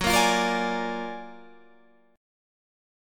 Gb+M9 Chord